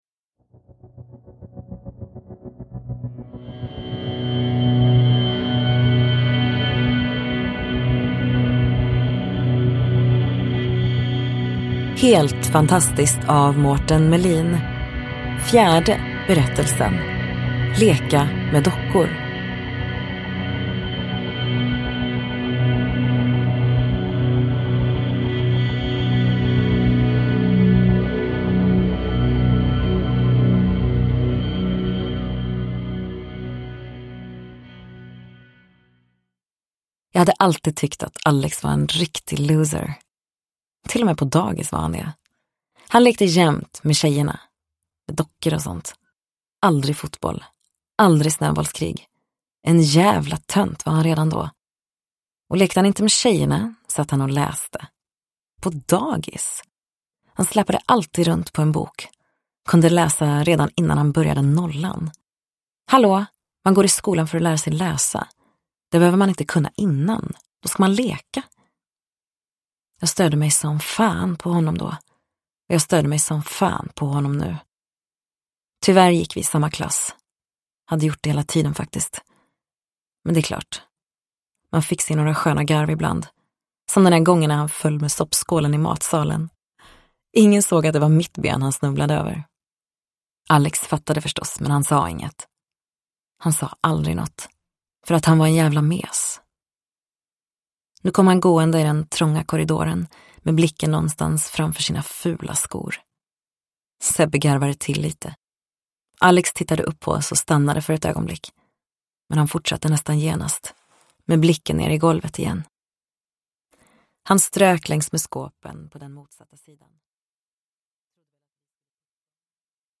Leka med dockor : en novell ur samlingen Helt fantastiskt – Ljudbok – Laddas ner